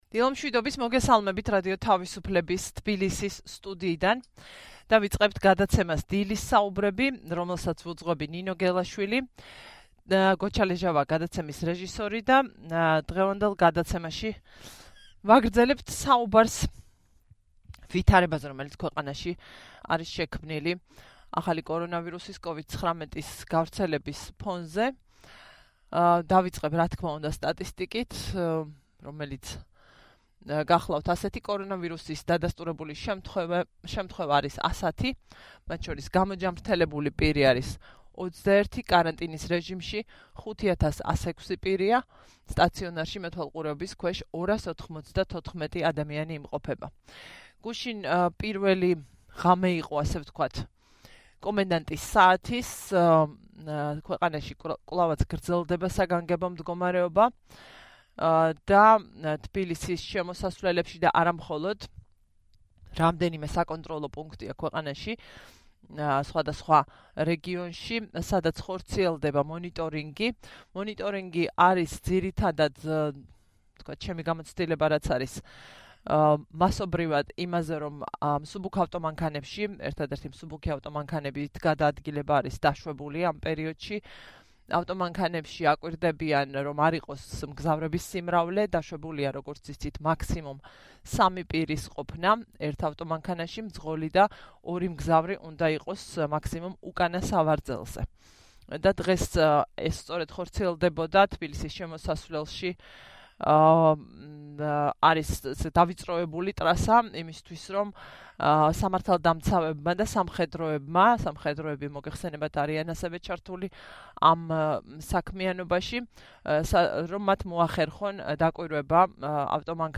„დილის საუბრების“ სტუმარი სამეცნიერო გამოკვლევების უახლეს შედეგებზე ყვება, რომლებიც შესაძლოა სასარგებლო იყოს ვირუსის გავრცელების ფონზე სწორი გადაწყვეტილებების მიღებისთვის: